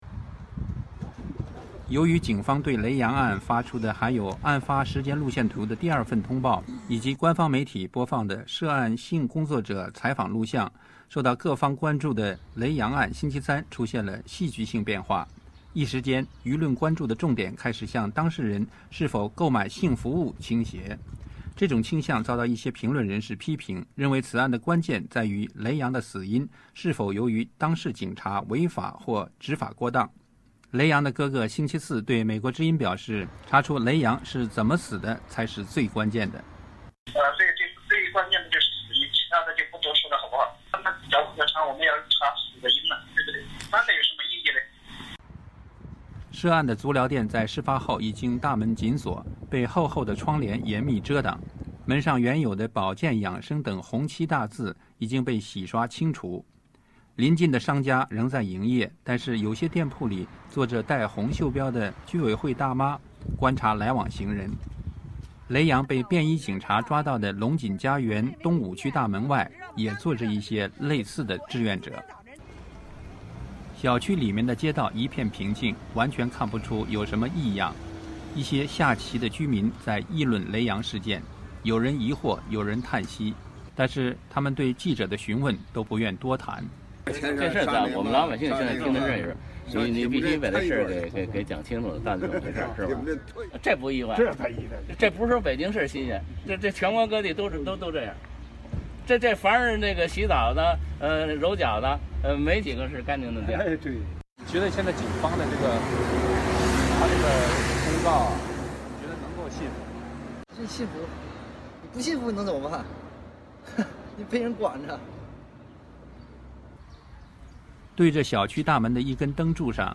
记者：（画外音）你们觉得对警方的这个公告信服吗？